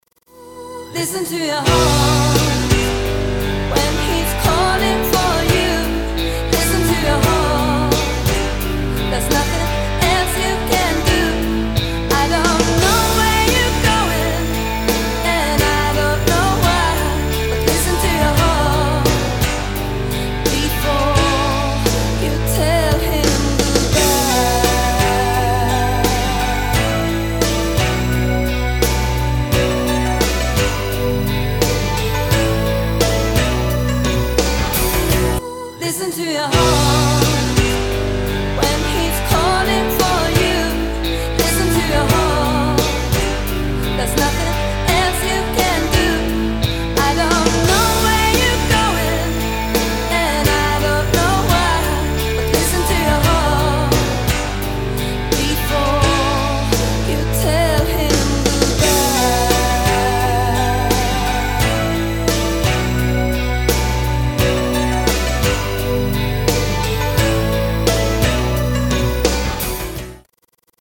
• Качество: 320, Stereo
красивые
Soft rock